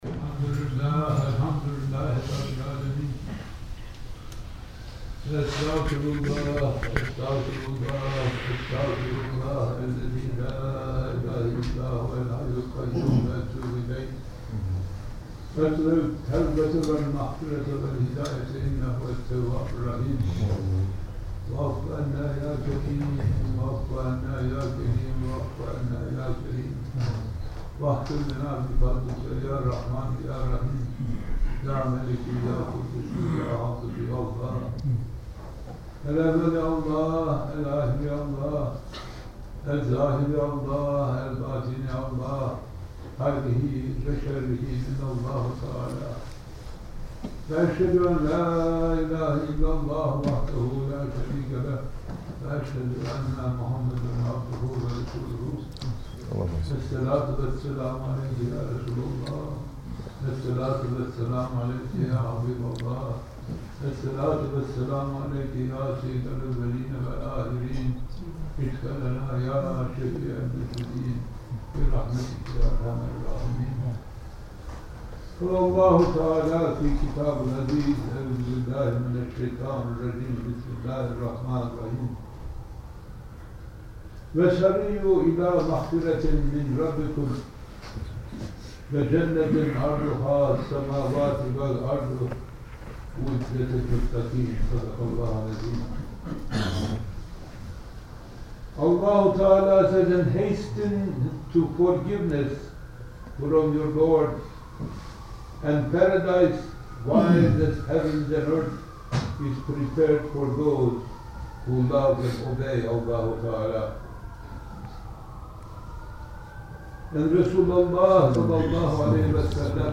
Jum`ah Khutba